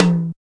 626 TOM1 HI.wav